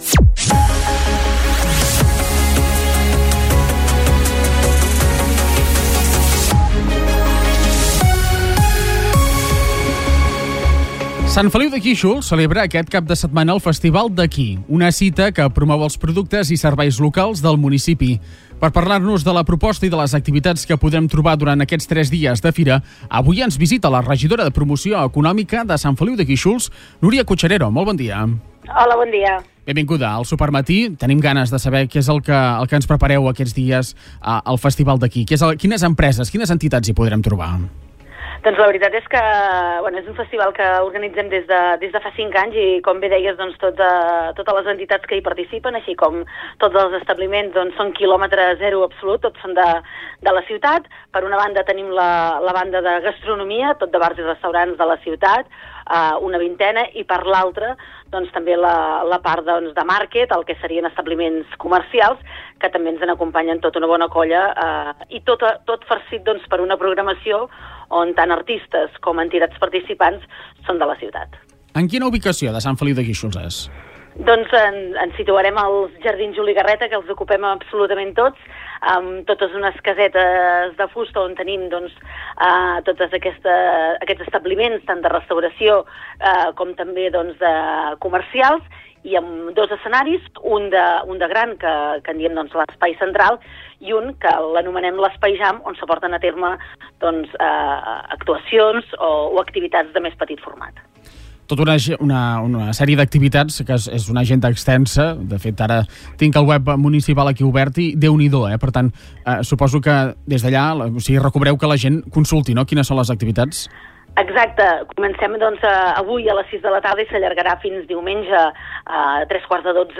Sant Feliu de Guíxols celebra aquest cap de setmana el Festival d’Aquí, una cita que promou els productes i serveis locals d’aquest municipi. Al Supermatí hem parlat amb la regidora de Promoció econòmica de l’Ajuntament del municipi, Núria Cucharero, per parlar de la proposta i de les activitats que podrem trobar durant els tres dies de fira.